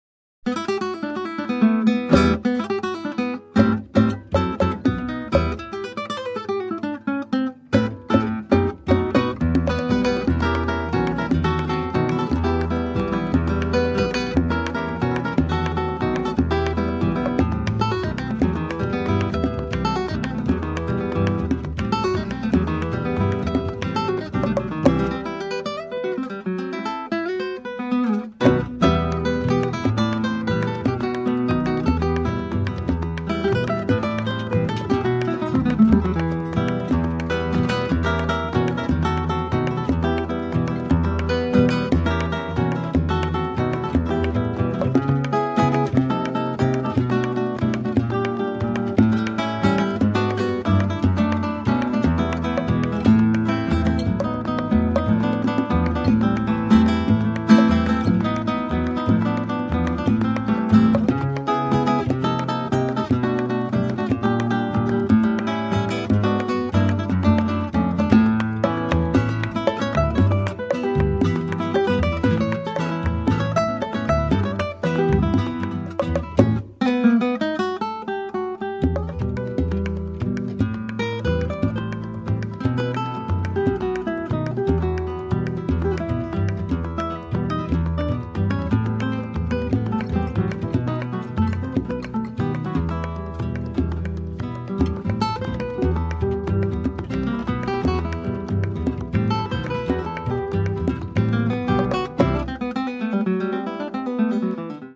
鮮烈かつ甘く響くギターが絶品です！
強力なエネルギーを放つかの如く発せられる瑞々しいギター・プレイが極上の味わいを産み出しています！